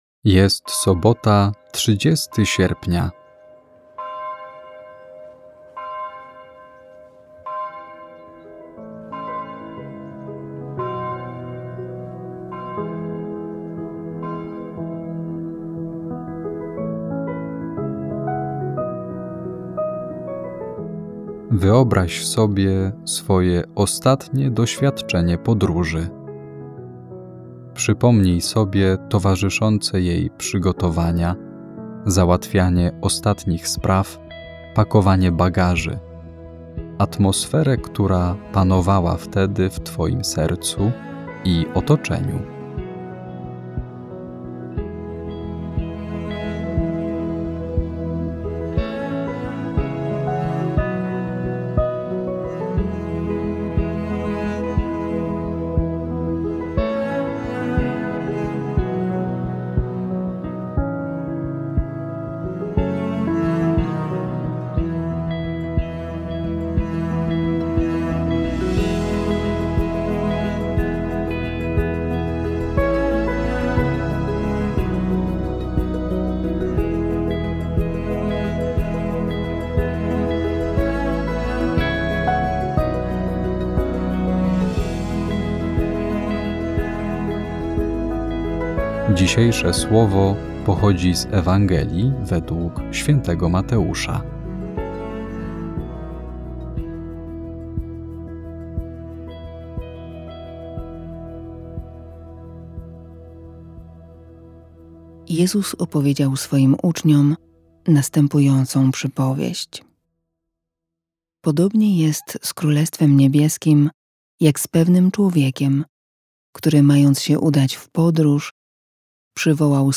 Kazanie Boże Narodzenie 2023
Kazanie ks. Proboszcza, wygłoszone w Boże Narodzenie 2023r.